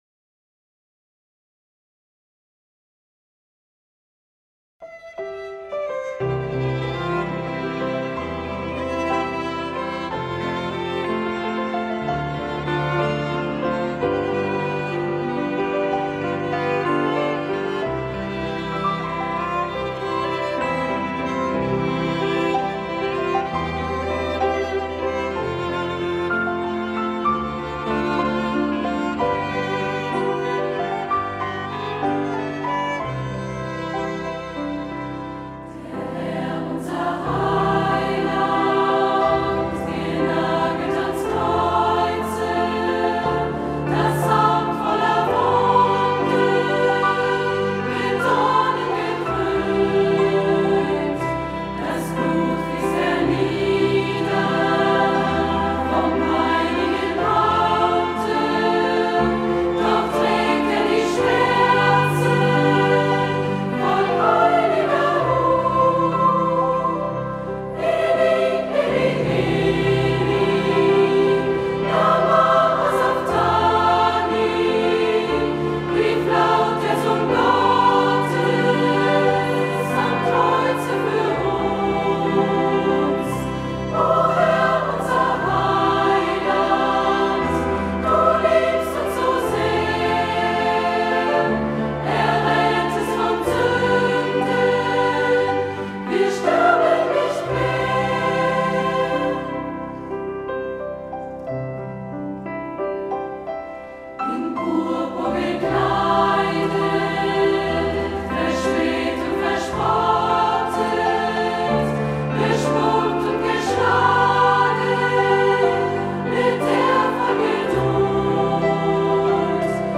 Passionssingen 2026